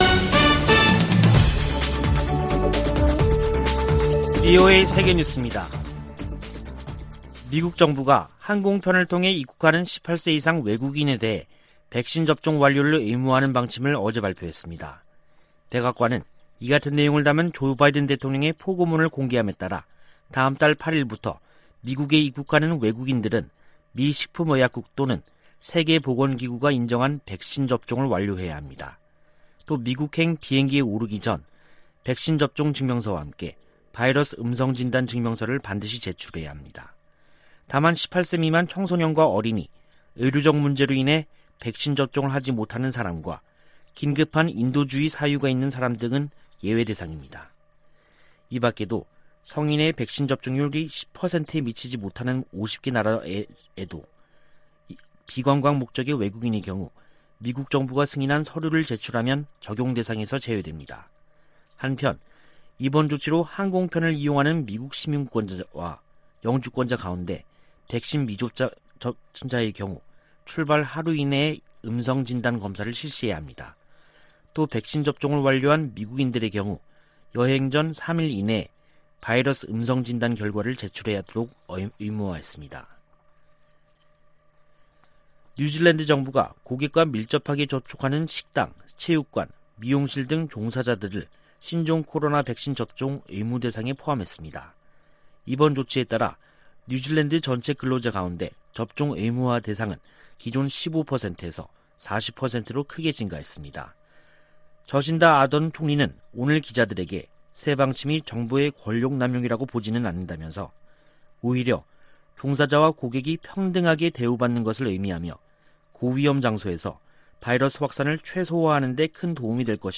VOA 한국어 간판 뉴스 프로그램 '뉴스 투데이', 2021년 10월 26일 2부 방송입니다. 조 바이든 미국 행정부는 대북정책 기조를 발표한 지 약 6개월이 지난 현재, 북한의 미사일 발사에 단호한 입장을 밝히면서도 한국 등과 대북 관여 방안을 모색하고 있습니다. 미 재무부가 최근 '인도주의 지원에 대한 영향 최소화' 등을 골자로 제재 검토 보고서를 낸데 관해 전문가들은 대북 제재 관련 실질적인 변화 가능성에 회의적인 견해를 밝혔습니다. 미국과 한국이 대북 인도적 지원을 위한 구체적 방안을 검토 중인 가운데 민간 구호단체들도 활동 재개를 준비하고 있습니다.